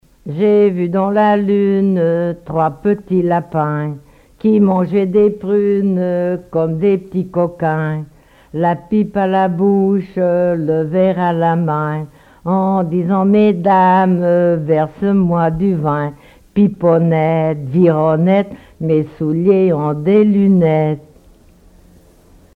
enfantine : comptine
collecte en Vendée
Témoignages et chansons traditionnelles
Pièce musicale inédite